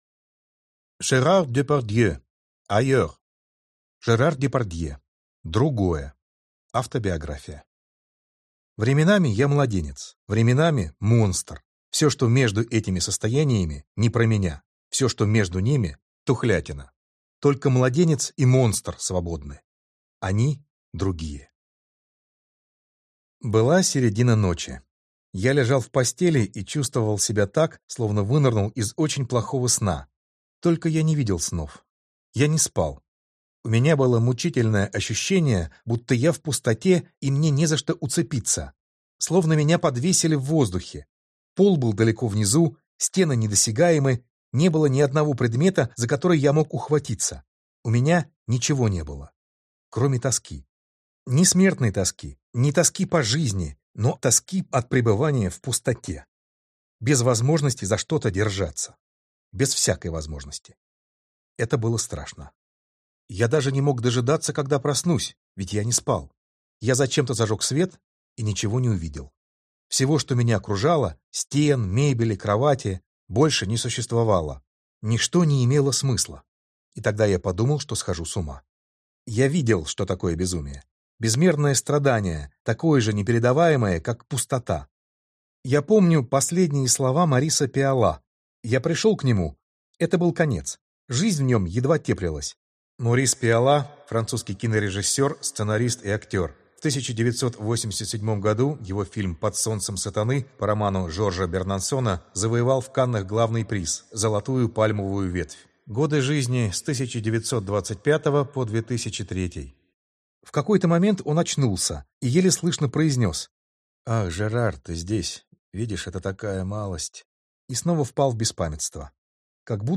Аудиокнига Другое. Автобиография | Библиотека аудиокниг